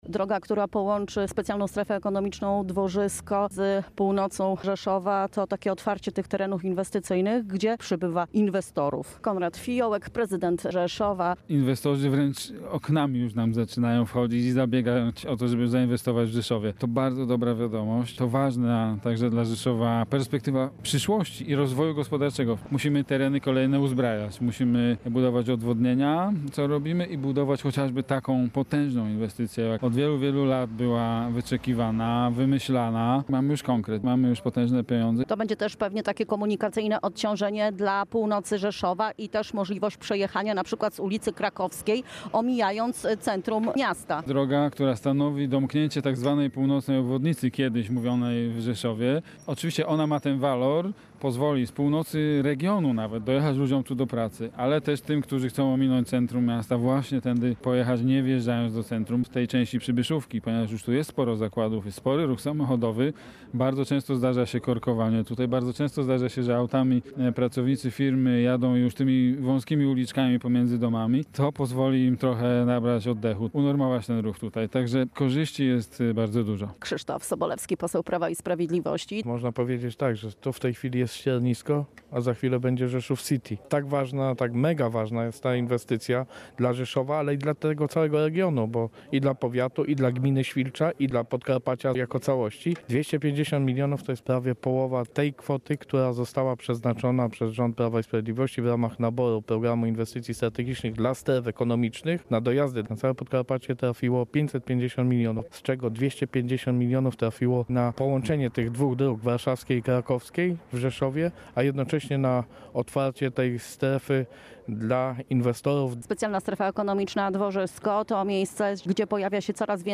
– powiedział prezydent Rzeszowa Konrad Fijołek.